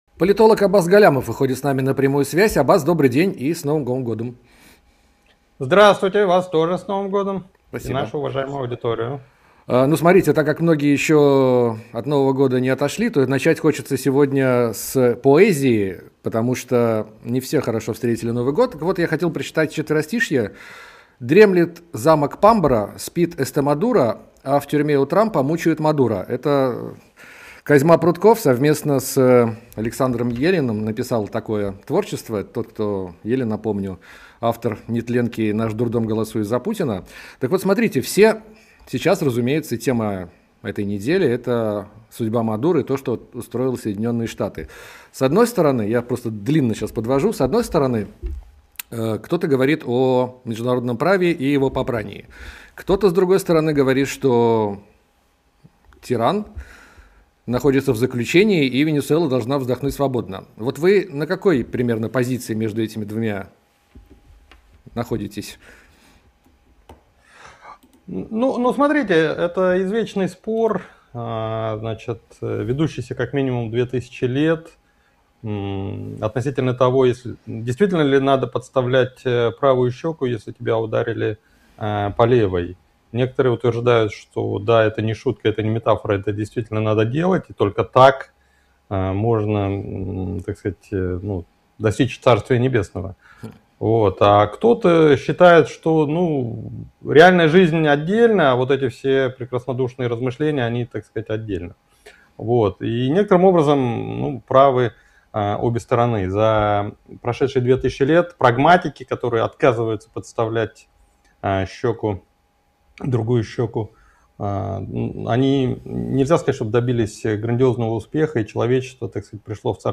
Аббас Галлямов политолог